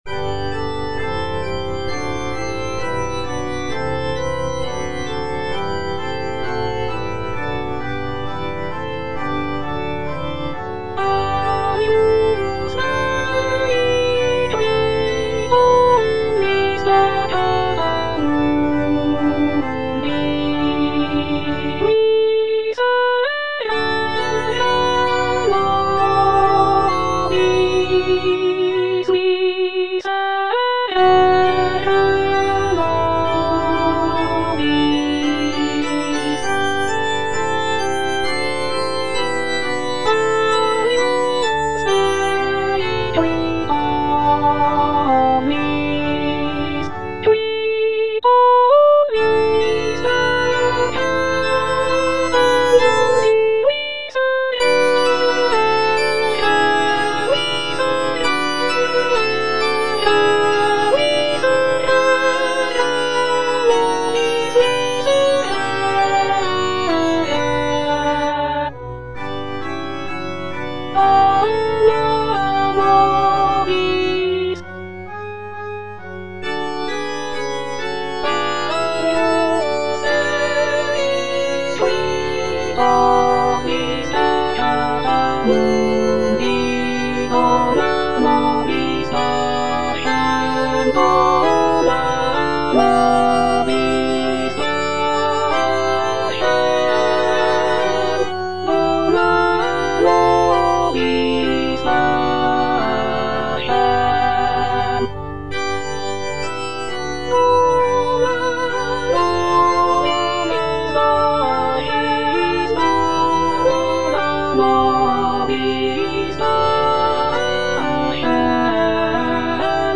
G. FAURÉ, A. MESSAGER - MESSE DES PÊCHEURS DE VILLERVILLE Agnus Dei (All voices) Ads stop: auto-stop
It was written in 1881 for a choir of local fishermen in Villerville, a small village in Normandy, France. The composition is a short and simple mass setting, featuring delicate melodies and lush harmonies.